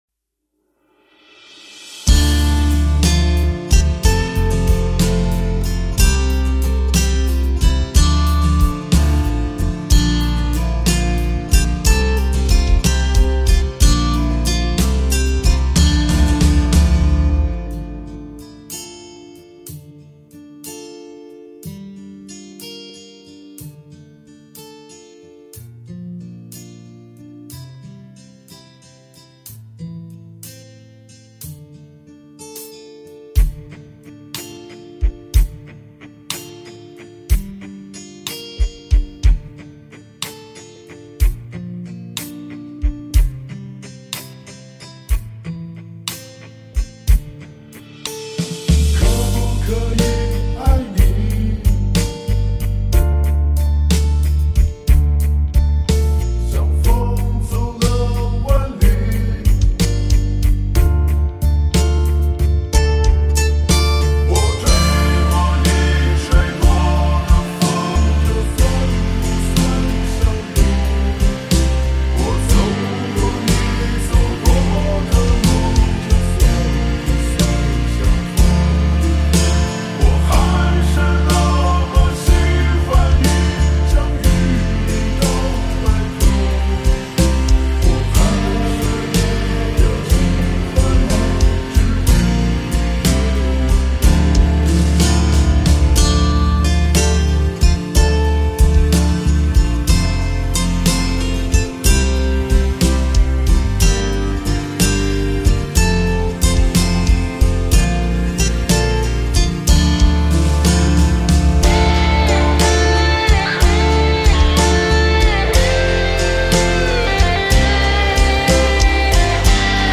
伴奏